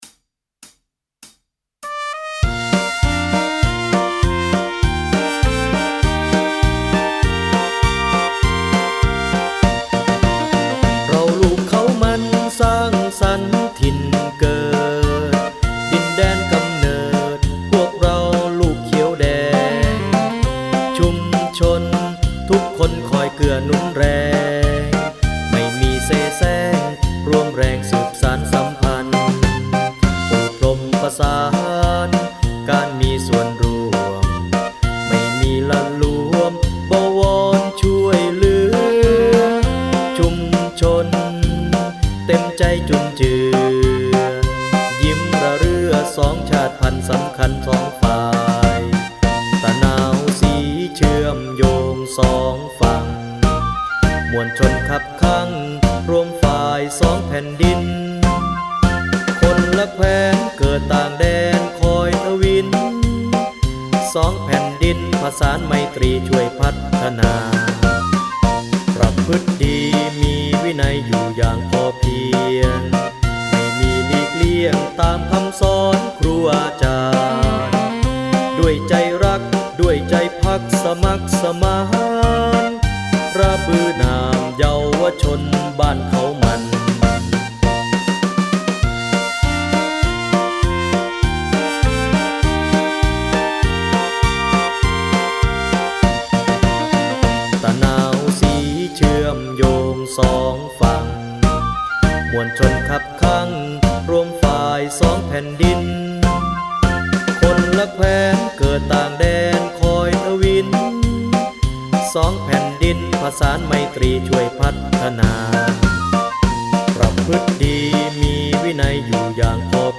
เพลง มาร์ชบ้านเขามัน ร้อง.mp3